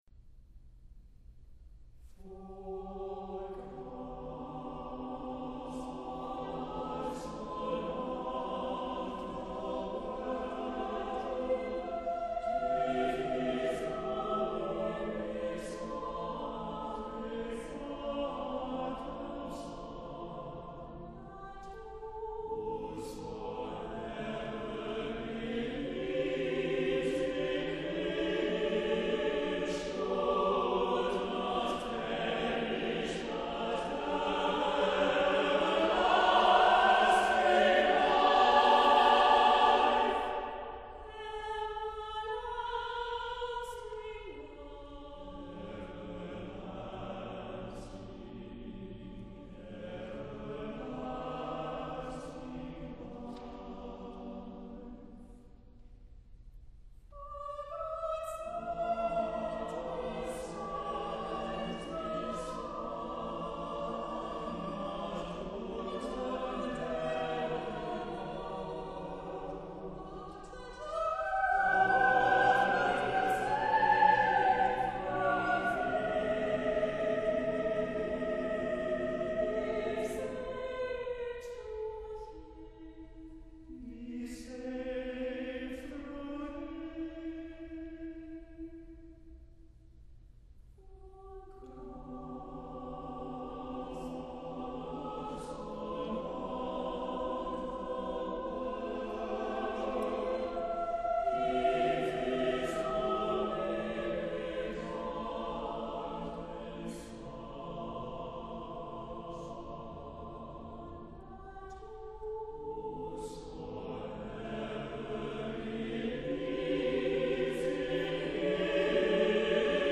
• Music Type: Choral
• Voicing: SATB
• Accompaniment: a cappella
• elegant motet with harmonic and melodic fluidity